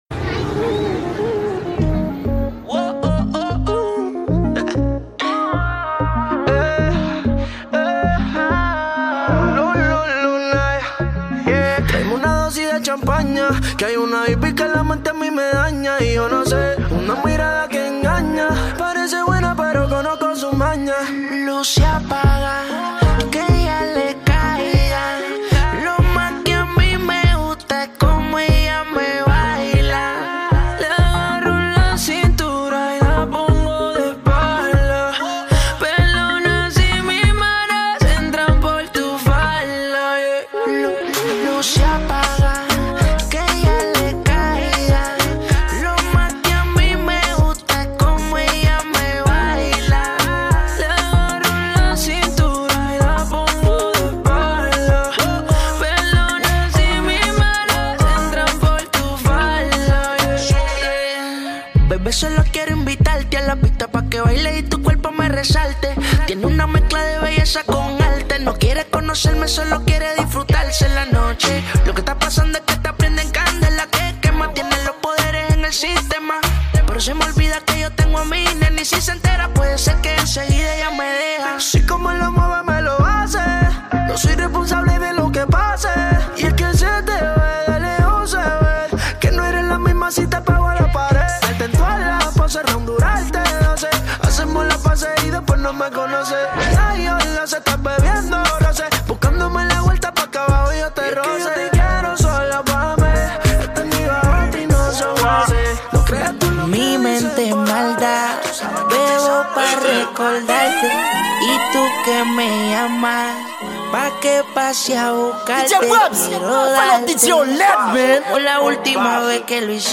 Genre: MIXES.